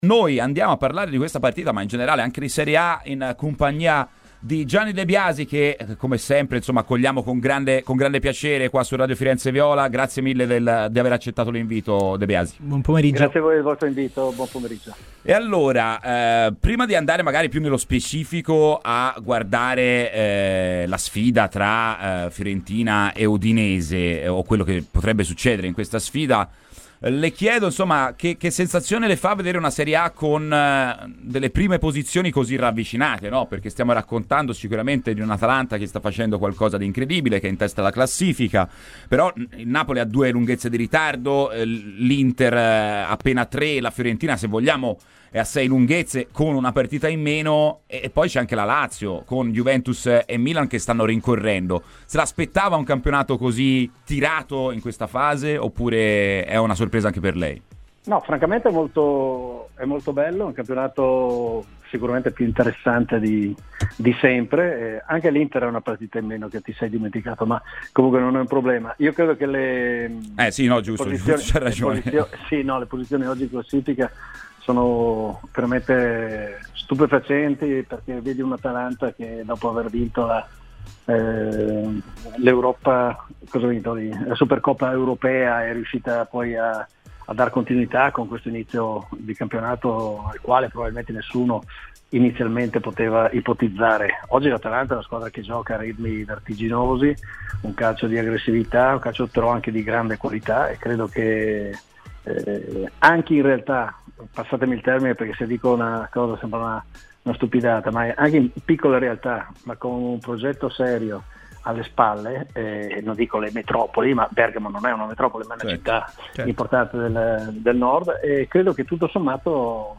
Il tecnico Gianni De Biasi è intervenuto a Radio FirenzeViola durante "Firenze in campo" per parlare di Fiorentina ma non solo, a partire dalla classifica di serie A: "E' un campionato bello, più interessante di sempre. Le posizioni in classifica sono stupefacenti, l'Atalanta dopo l'Europa League sta giocando anche per il campionato perché anche piccole città rispetto alle metropoli ma con un progetto serio possono fare calcio anche senza grandi investimenti".